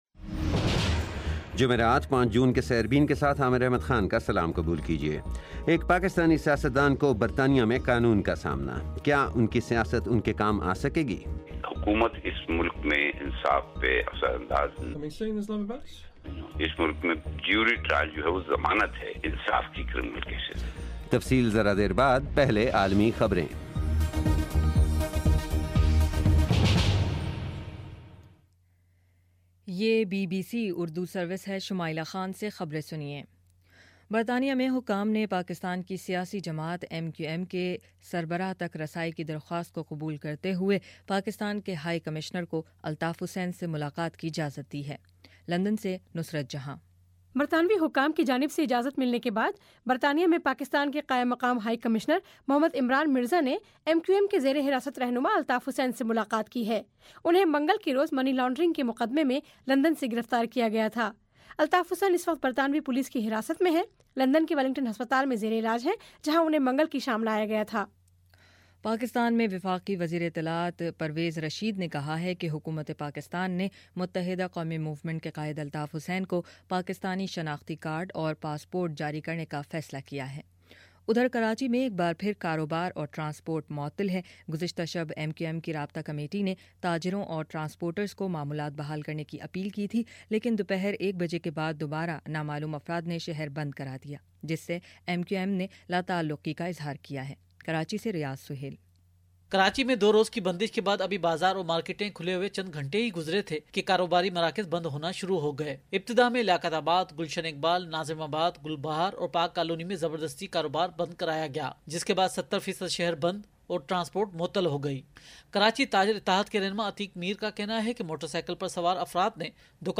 جمعرات 5 جون کا سیربین ریڈیو پروگرام